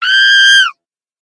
「キー！」チンパンジーの鳴き声。
猿の鳴き声 着信音